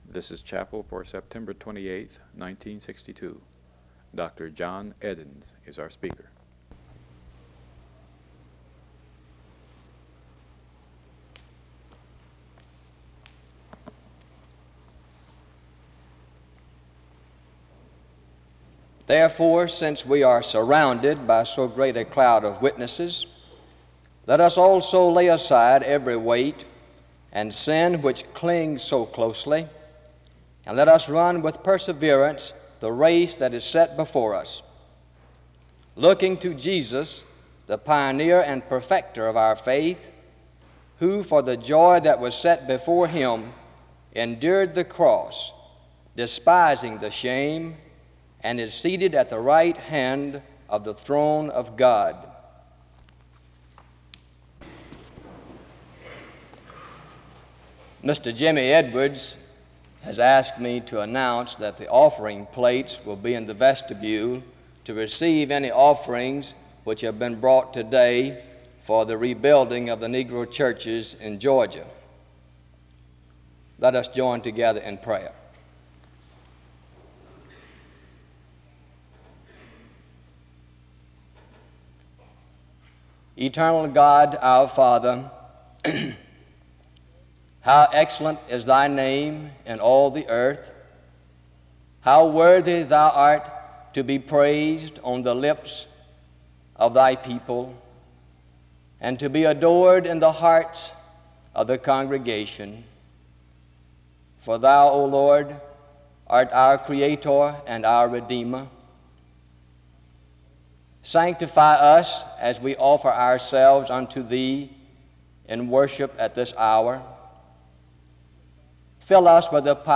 The students sing a hymn from 2:38-5:08.